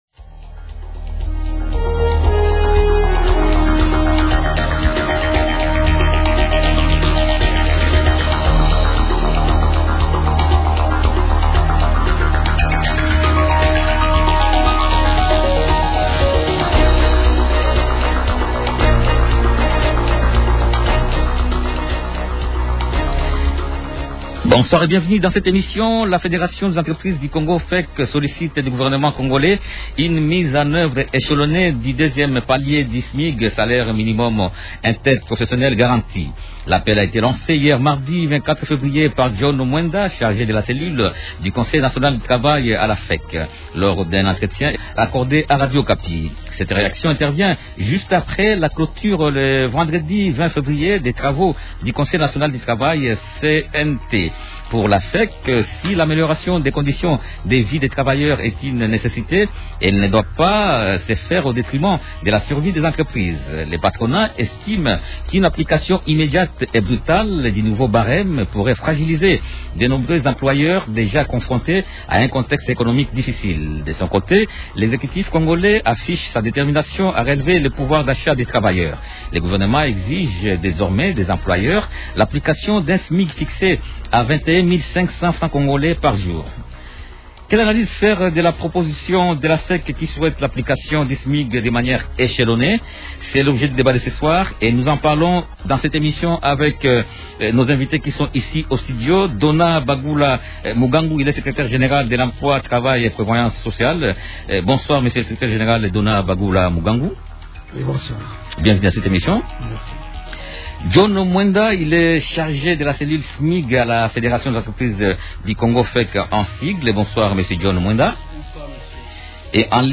Invités : -Donat Bagula Mugangu, secrétaire général de l’Emploi et travail.